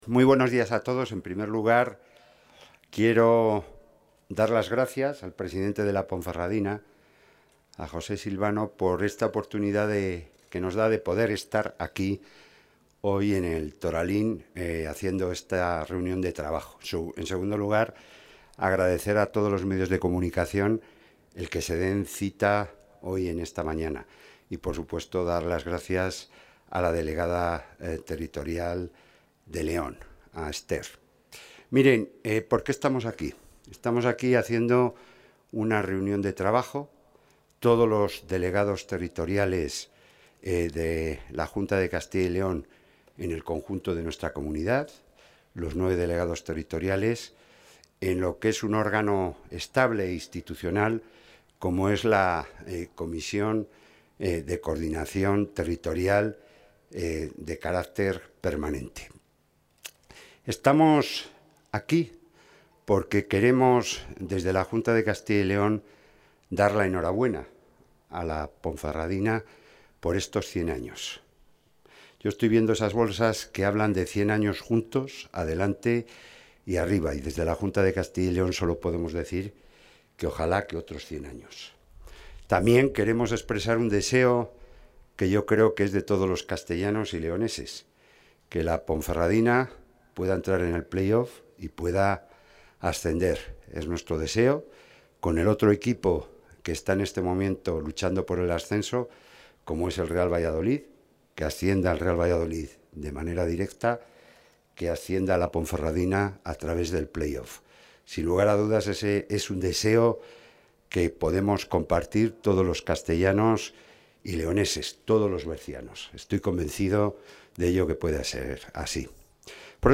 Intervención del consejero de la Presidencia.
Así ha explicado esta mañana en Ponferrada el consejero de la Presidencia, Jesús Julio Carnero, el objetivo de uno de los puntos fuertes de la presente legislatura.